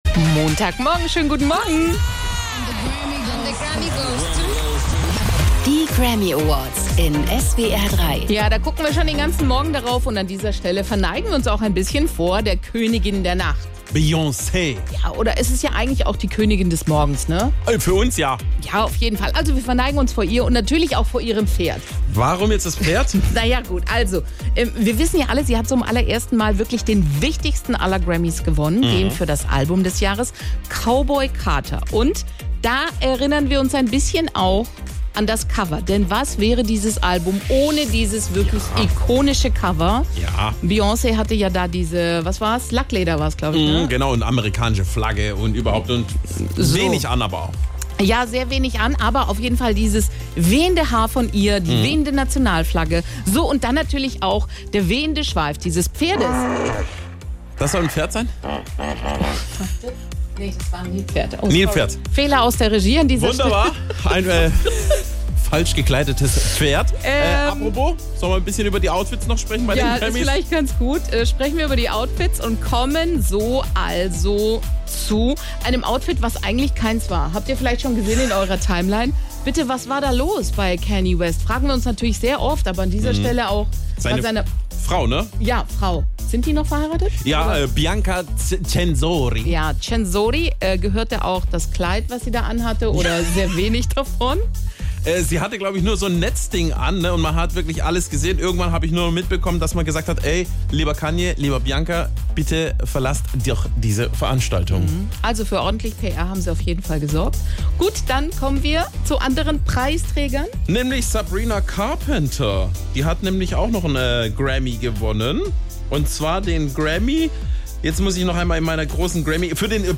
Nachrichten „Verneigen uns vor der Königin der Nacht“